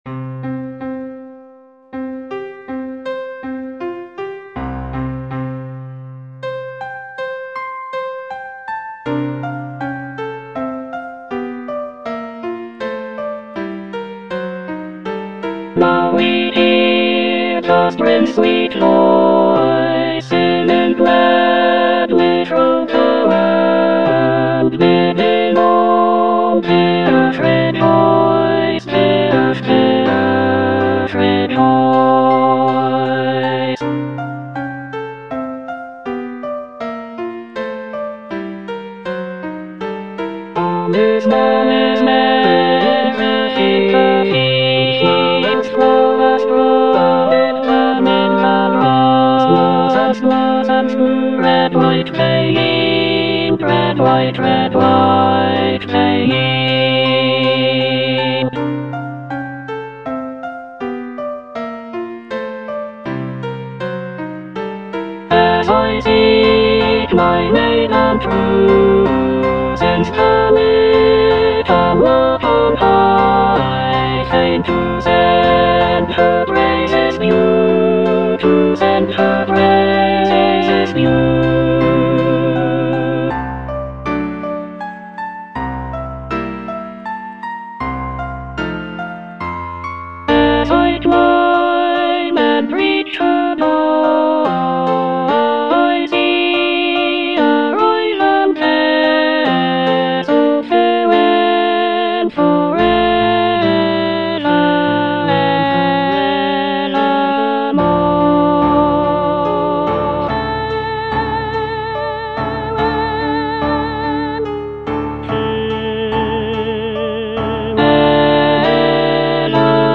E. ELGAR - FROM THE BAVARIAN HIGHLANDS False love - Alto (Emphasised voice and other voices) Ads stop: auto-stop
The music captures the essence of the picturesque landscapes and folk traditions of the area, with lively melodies and lush harmonies.